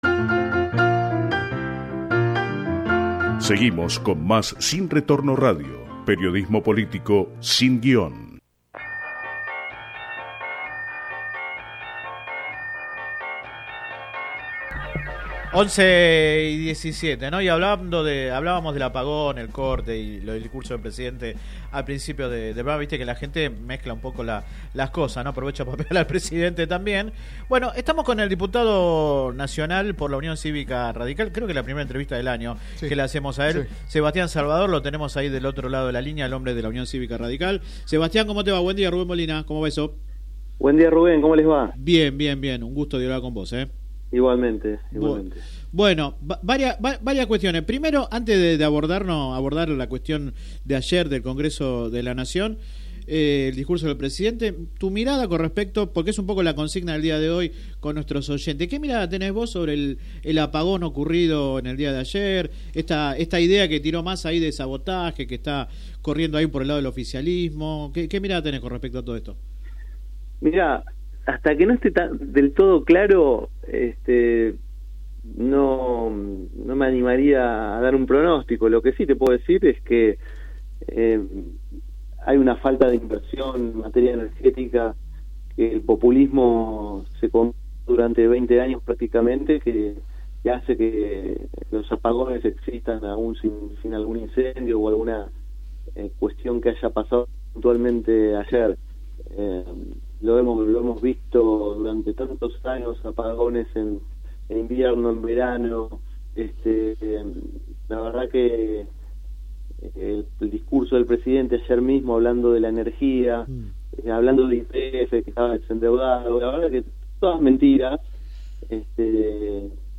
El legislador habló en exclusivo en el programa radial Sin Retorno (lunes a viernes de 10 a 13 por GPS El Camino FM 90 .7 y AM 1260).